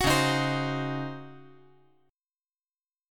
C#M7sus2sus4 Chord
Listen to C#M7sus2sus4 strummed